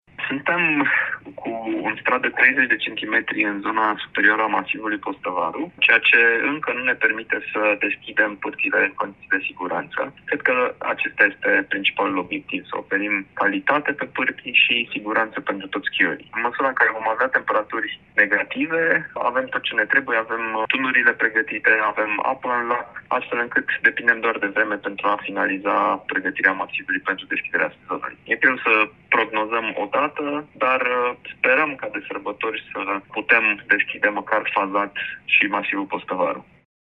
Primarul municipiului Brașov, Allen Coliban: